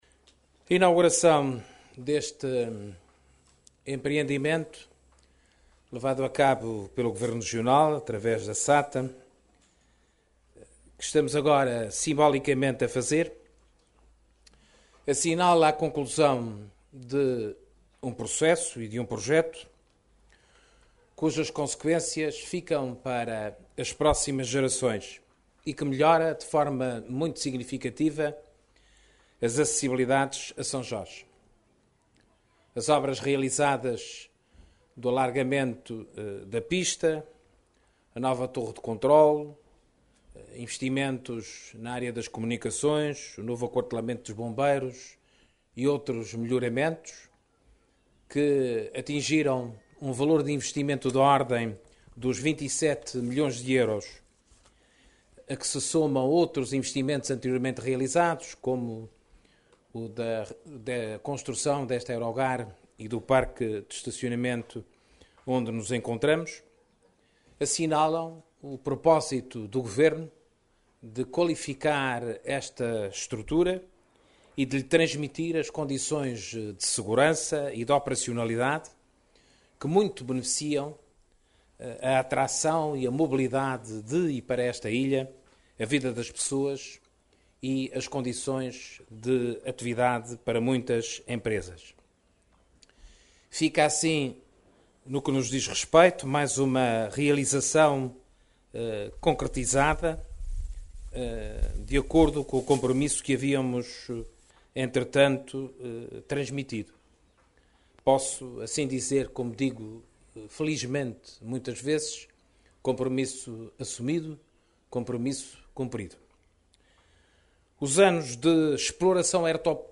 Carlos César falava ontem ao final da tarde na inauguração da ampliação e alargamento da pista e outras obras no Aeroporto de São Jorge.